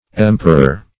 Emperor \Em"per*or\, n. [OF. empereor, empereour, F. empereur,